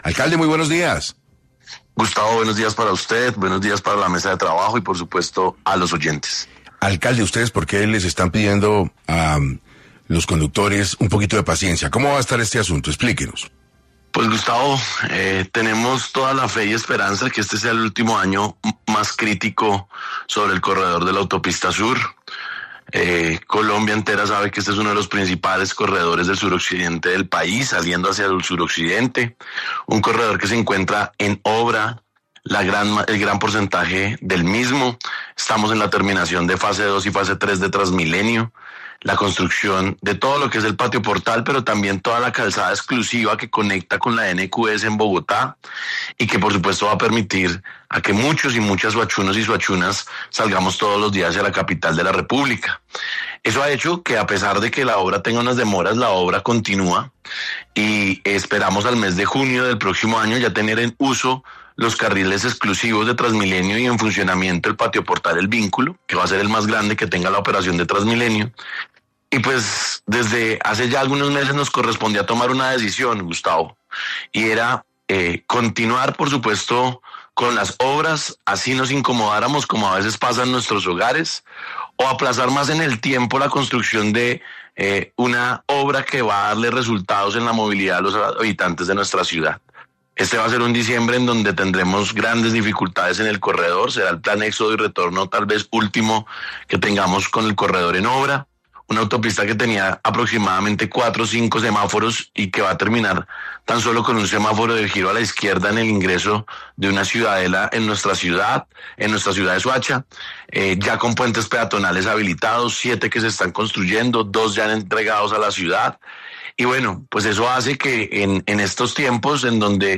Frente a este tema, el alcalde de Soacha, Julián Sánchez, se refirió en entrevista a 6AM a las dificultades que pueden tener los ciudadanos al movilizarse en esta vía, la cual tiene gran importancia para llegar a los departamentos de Tolima y Huila, así como a otros lugares en el sur de Colombia.